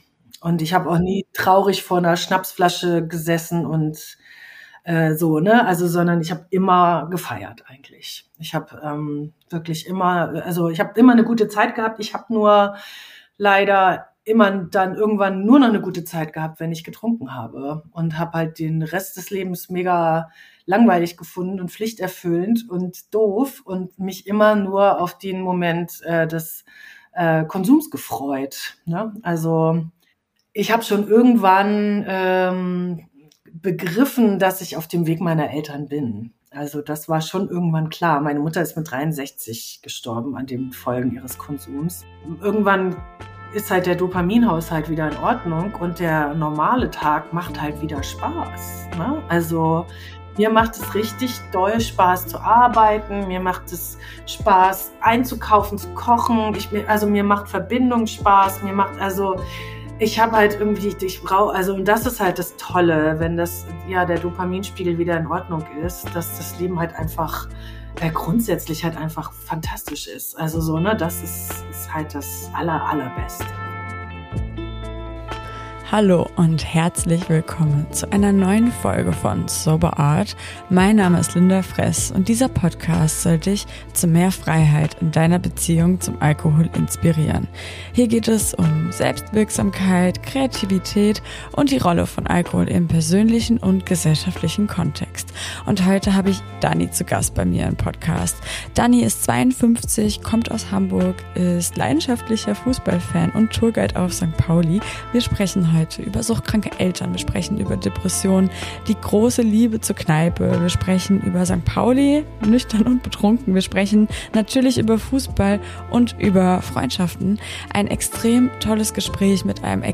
Wir sprechen über suchtkranke Eltern, über Depressionen, über die Liebe zur Kneipe, über St. Pauli, über Fußball und Freundschaften. Ein extrem tolles Gespräch mit einem extrem tollen Menschen.